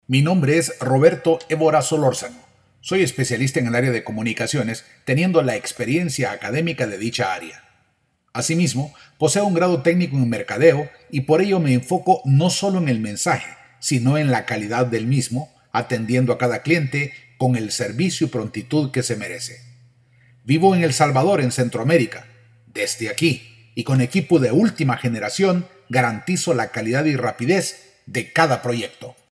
Spanish native speaking voice over, with a Neural Tone.
Sprechprobe: Sonstiges (Muttersprache):